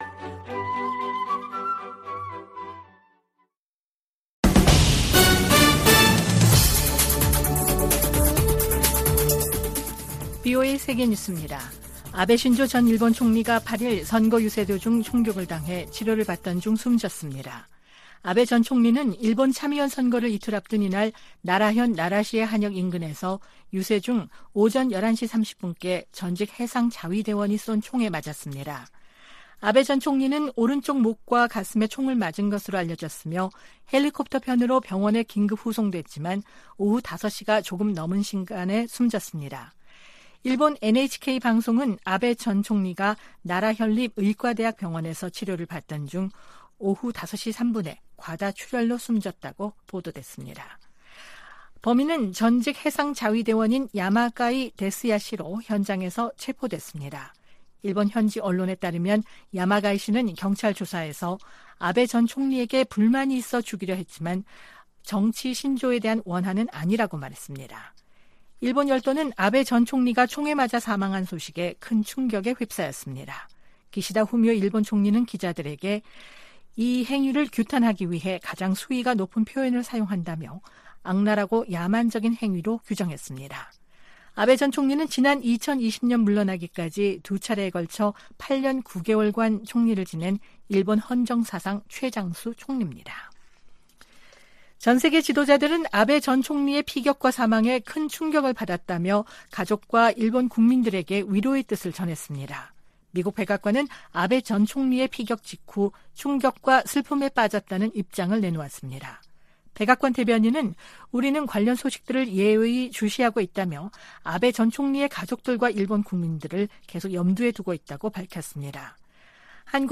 VOA 한국어 아침 뉴스 프로그램 '워싱턴 뉴스 광장' 2022년 7월 9일 방송입니다. 미국과 한국, 일본이 G20 외교장관회의를 계기로 3국 외교장관 회담을 열고 북한 문제 등을 논의했습니다. 미의회에서는 북핵 문제를 넘어 미한일 공조를 강화하려는 움직임이 두드러지고 있습니다. 미 국무부의 데릭 촐렛 특별보좌관이 조태용 주미 한국대사를 만나 미한일 협력 강화 방안 등을 논의했습니다.